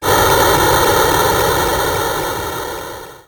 Machine21.wav